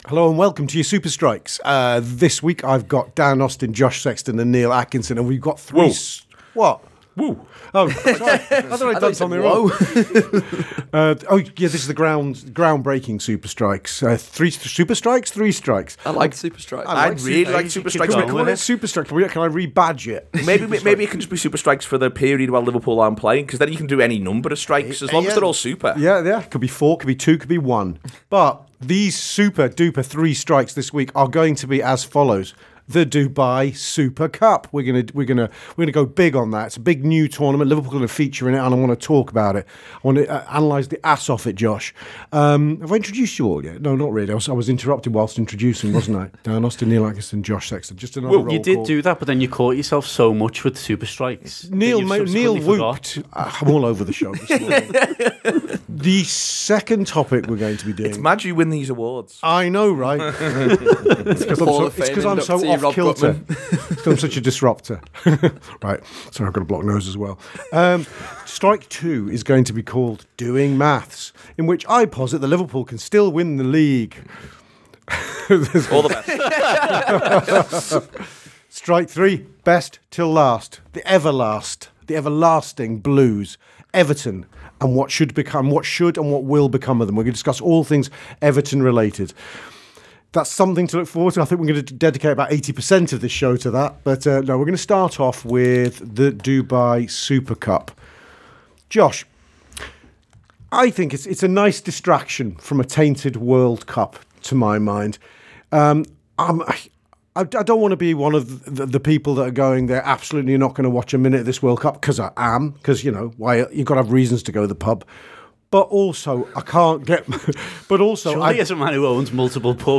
Below is a clip from the show – subscribe for more on the Dubai Super Cup…